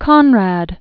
(kŏnrăd), Joseph Originally Jósef Korzeniowski. 1857-1924.